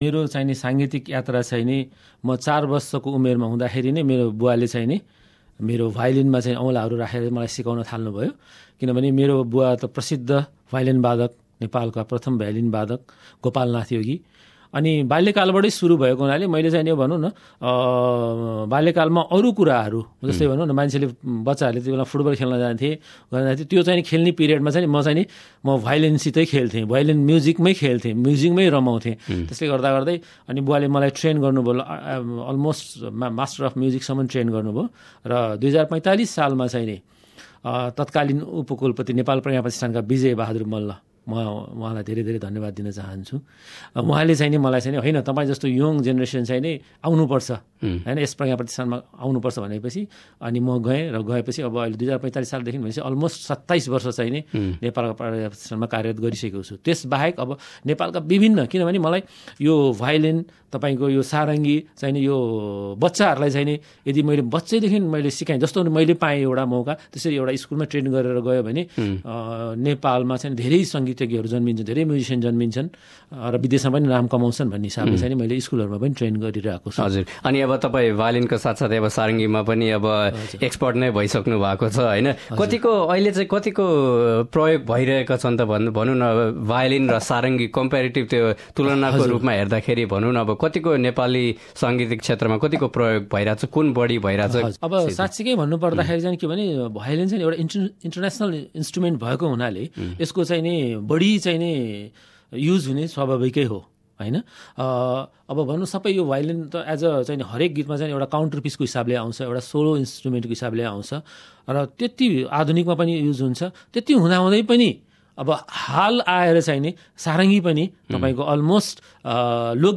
speaking to SBS Nepali